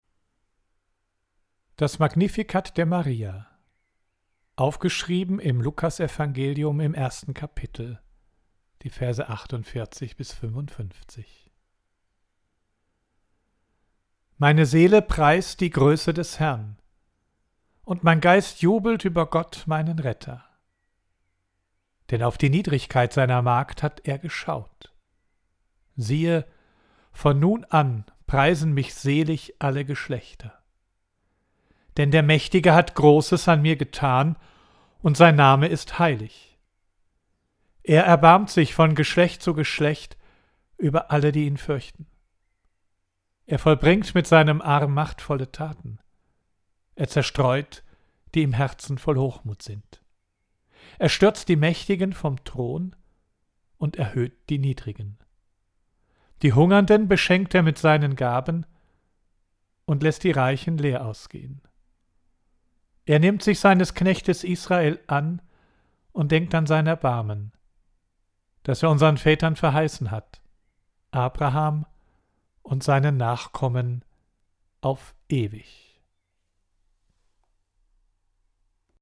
Audio II: Das ganze Gedicht